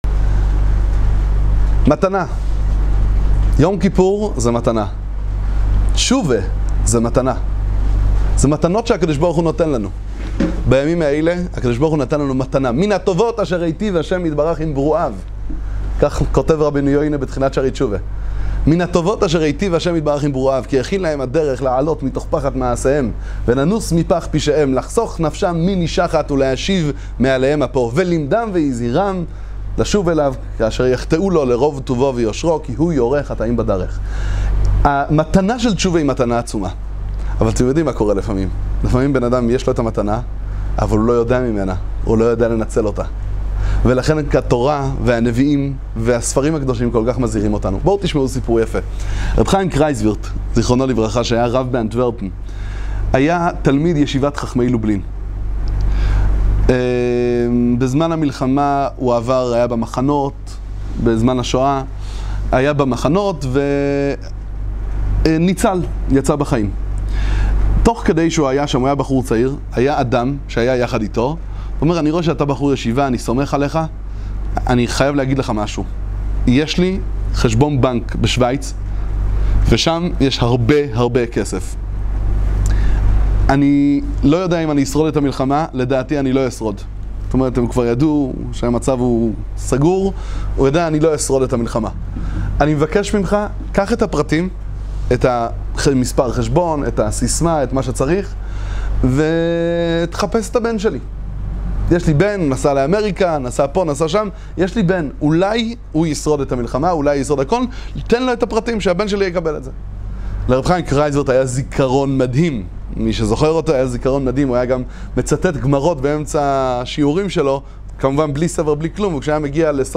המתנה של תשובה – דבר תורה קצר עם סיפור ליום הכיפורים